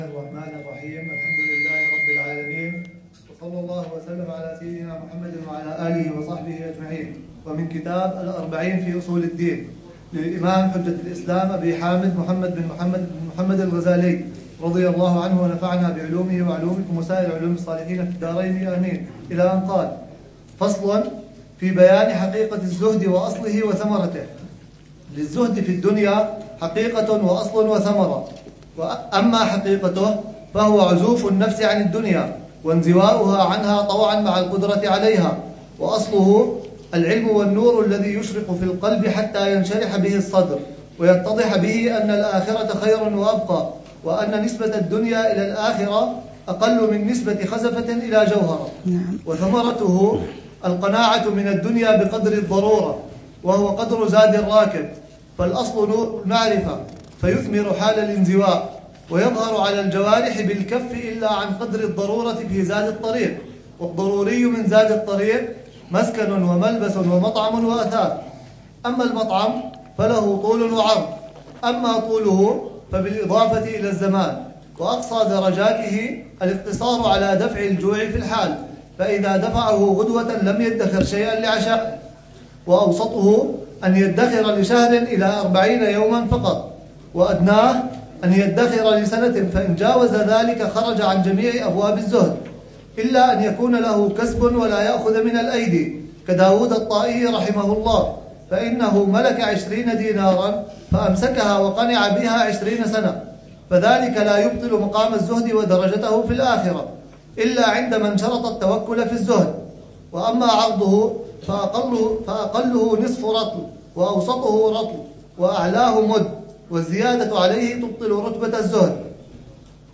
الدرس ال34 في شرح الأربعين في أصول الدين: الزهد: بيان حقيقته، حدود الضرورة، ودرجات السائرين، وإطلالة على الصبر